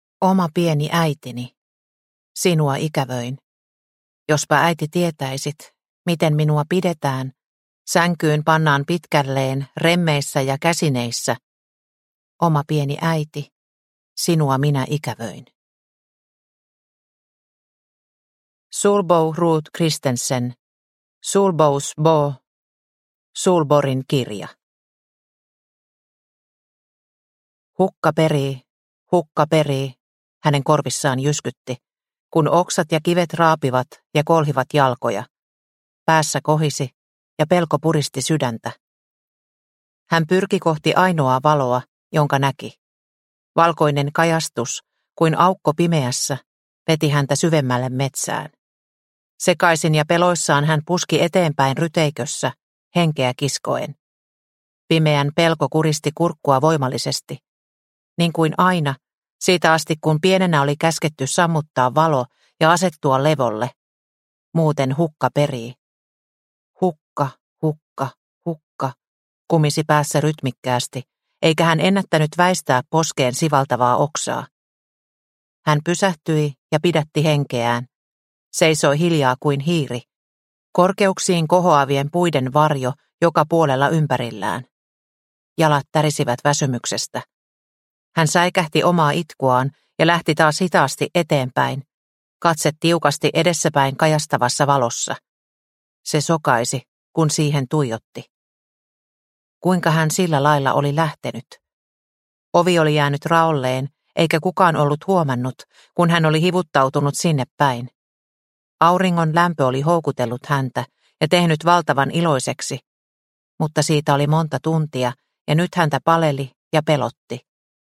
Unohdetut tytöt – Ljudbok – Laddas ner